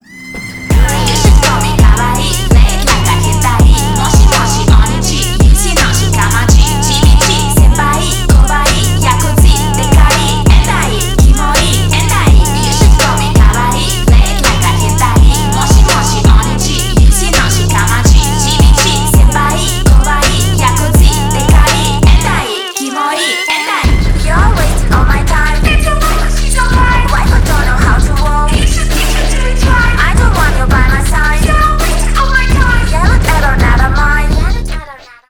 Ремикс
клубные # громкие